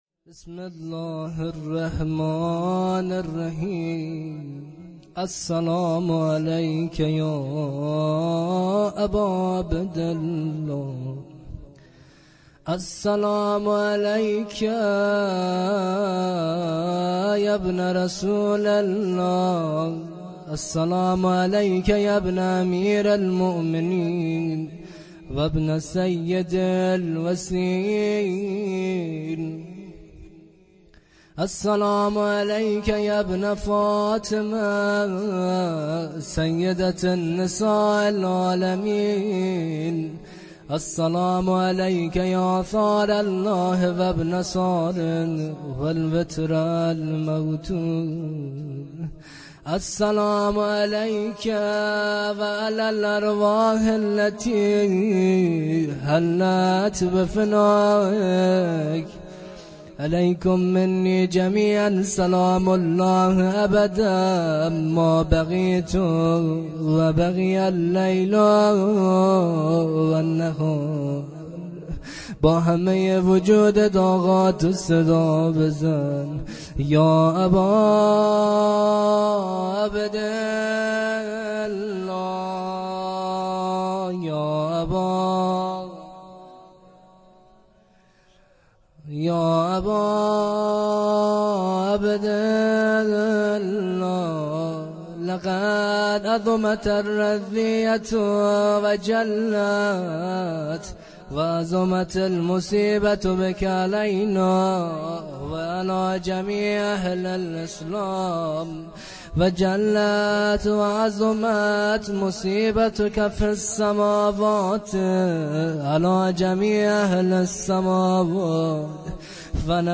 زمزمه زیارت عاشورا
شب سوم ویژه برنامه فاطمیه دوم ۱۴۳۹